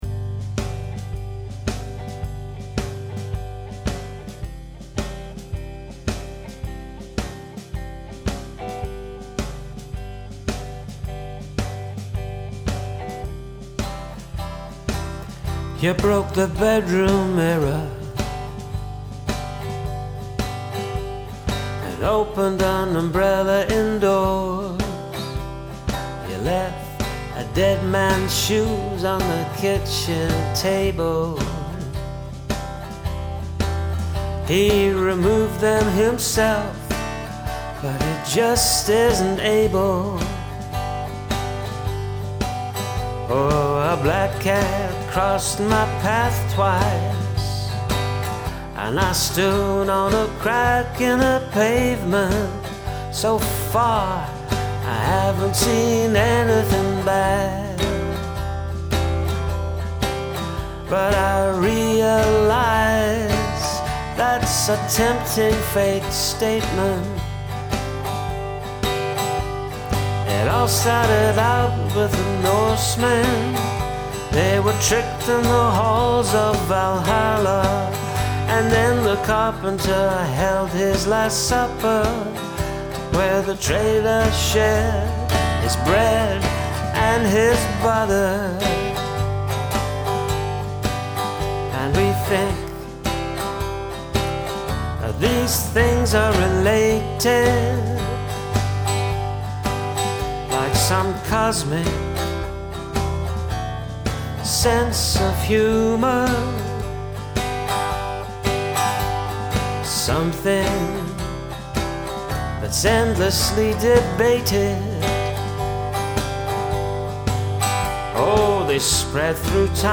Had another go, this time a bit more jaunty